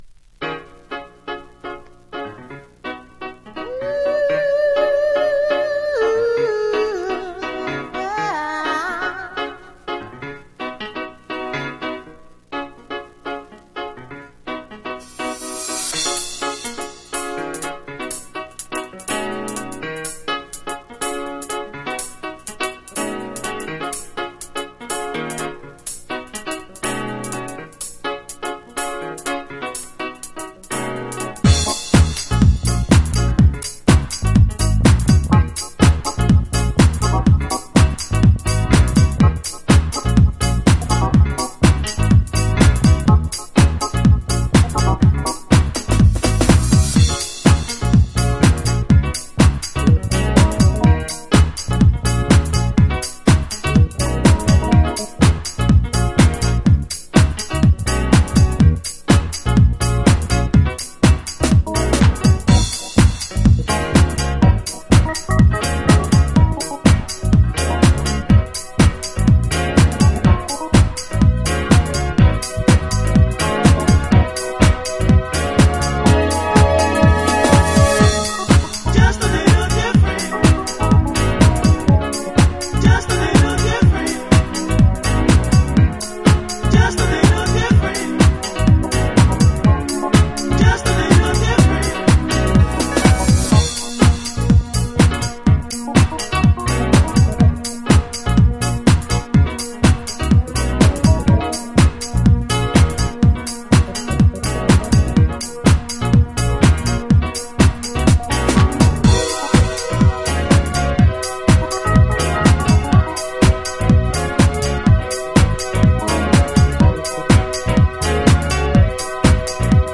ゴスペルハウス古典。
Extended Instrumental